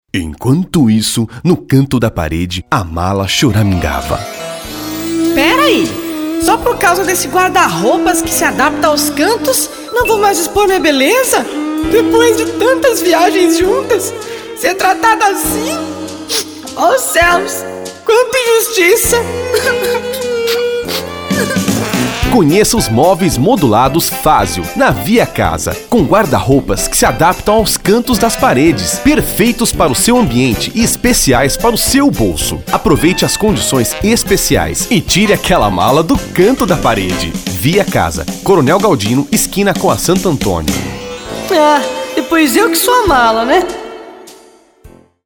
Masculino
Marilan - Pit Stop (Voz interpretada)